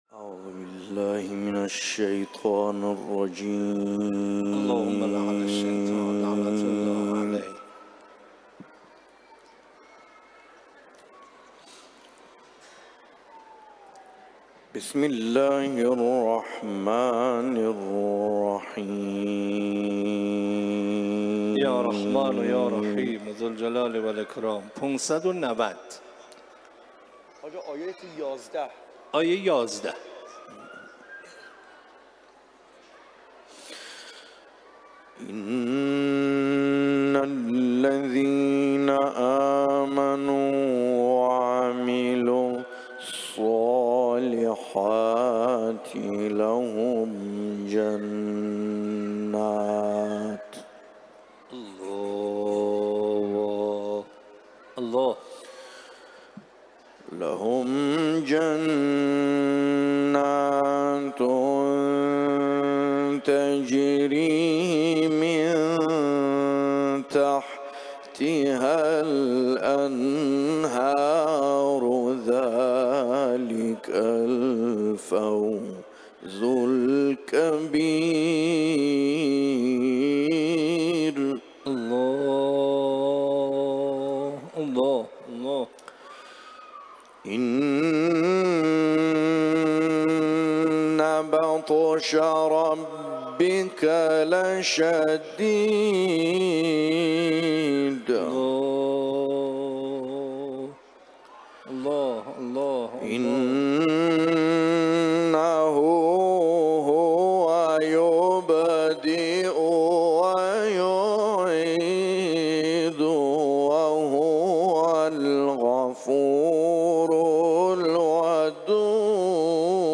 تلاوت قرآن ، سوره بروج